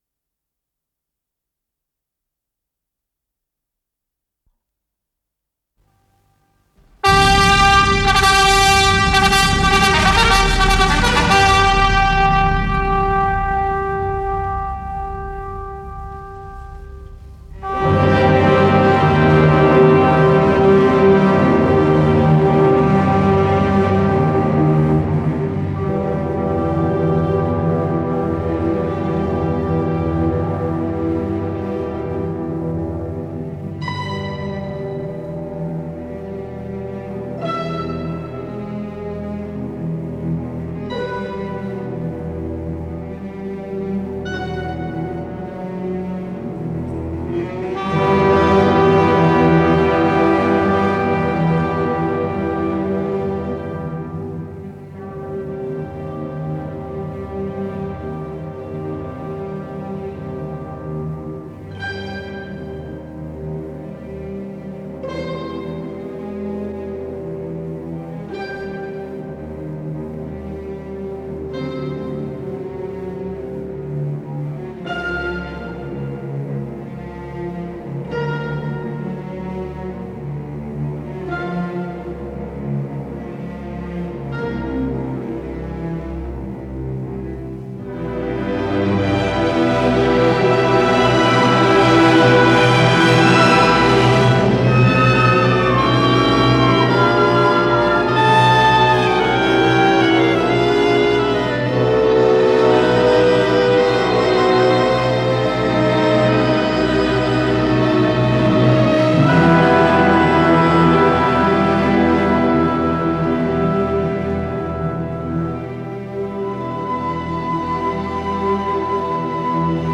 с профессиональной магнитной ленты
ИсполнителиСимфонический оркестр Всесоюзного радио и Центрального телевидения
ВариантДубль моно